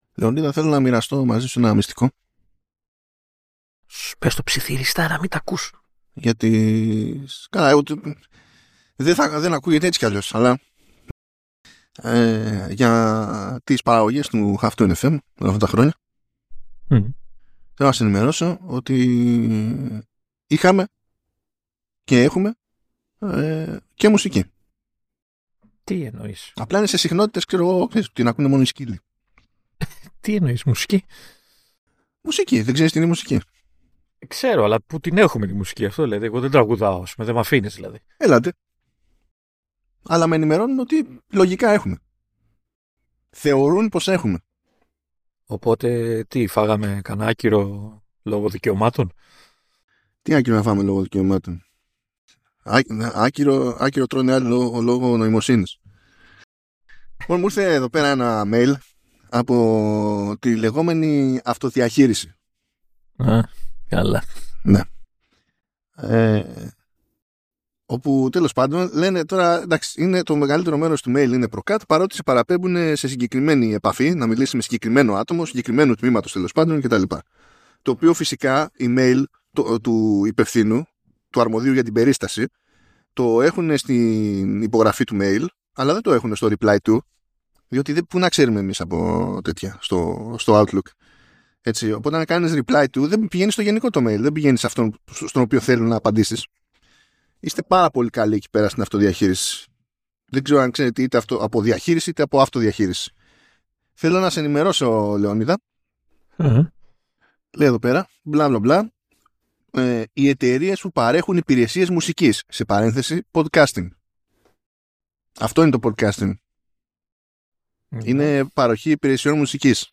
Ο αγγλόφωνος κόσμος προσπαθεί να μας τρελάνει, οτιδήποτε περνιέται για φήμη αν σχετίζεται με iPhone 17, ακόμη ξεφυτρώνουν φιλοπράγματα για το iPhone 16e και όλα αυτά χωρίς μουσική υπόκρουση.